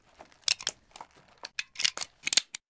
PP-Reload.ogg